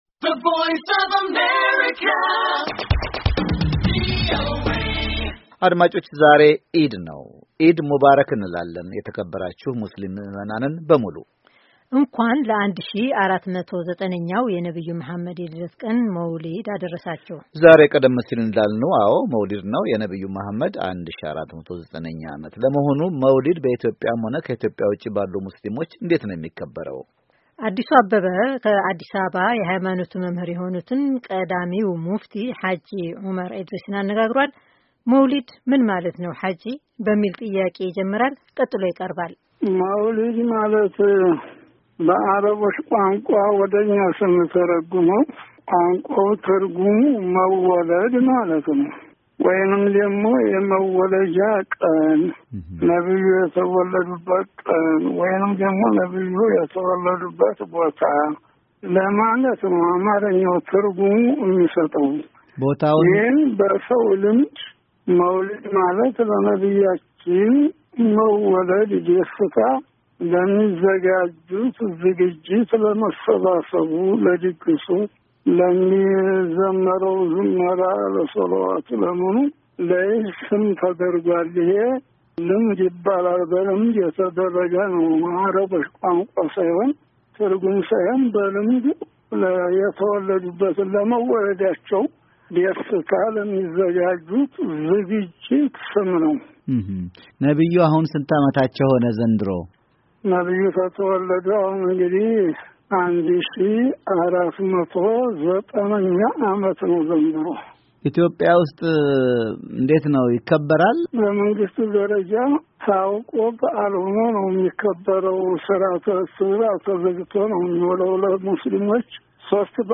የእሥልምና መምህሩ ቀዳሚ ሙፍቲ ሃጂ ኦማር ኢድሪስ ለቪኦኤ መልዕክታቸውን አሰምተዋል።